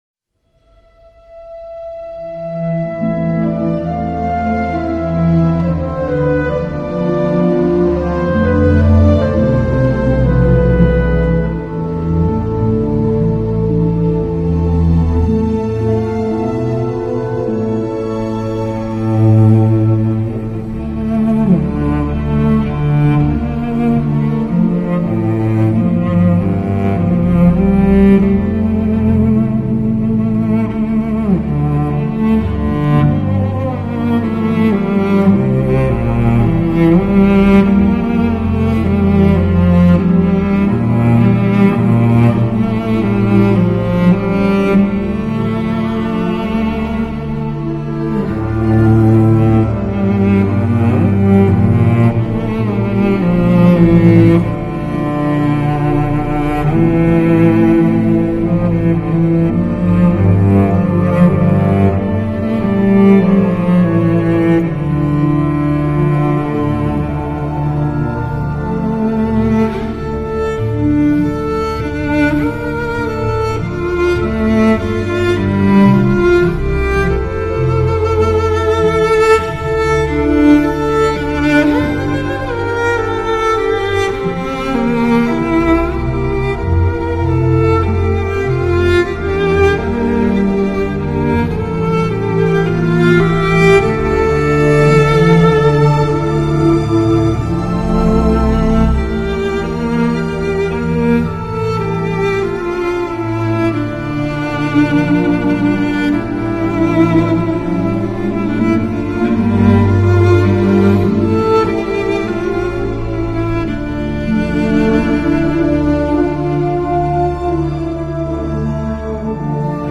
整体乐声纯净、温暖。
专辑采用顶级开盘带模拟录音机录制，音乐表现原始纯真，清晰卓越，令你有置身于现场欣赏的感觉。